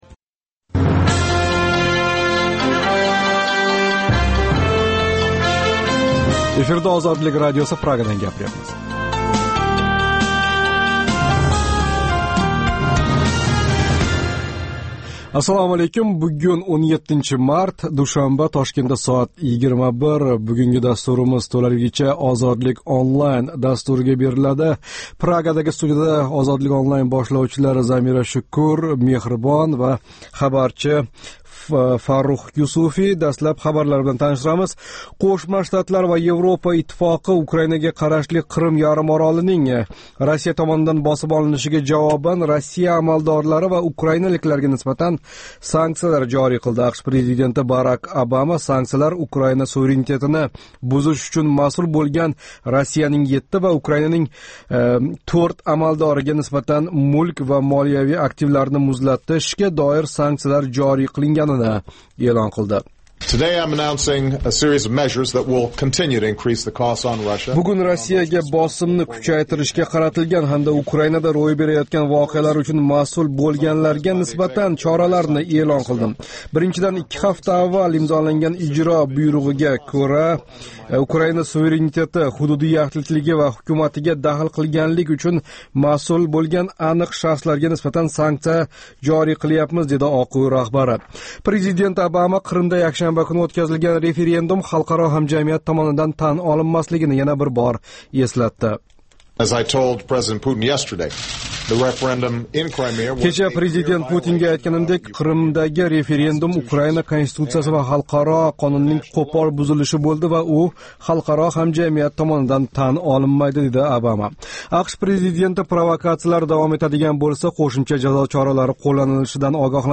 “Ozodlik Online” - Интернетдаги энг замонавий медиа платформаларни битта тугал аудио дастурга бирлаштирган Озодликнинг жонли интерактив лойиҳаси.